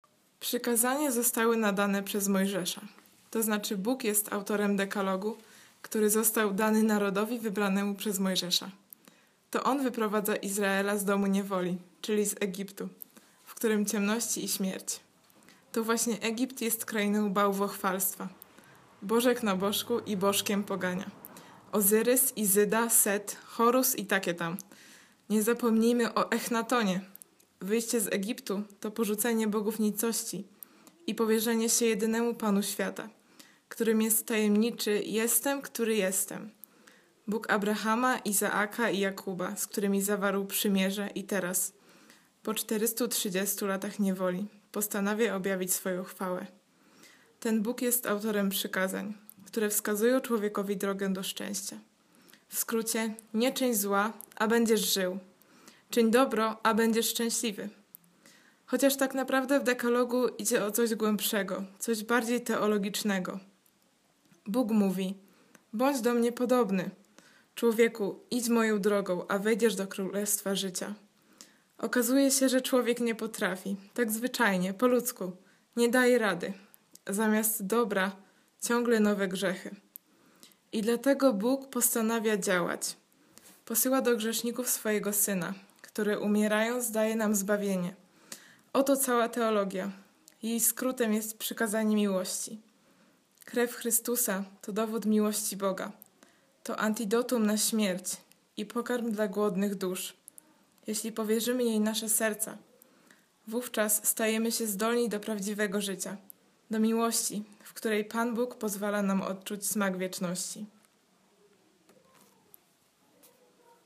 wakacyjne warsztaty studentów KUL w Zakopanem